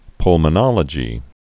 (plmə-nŏlə-jē, pŭl-)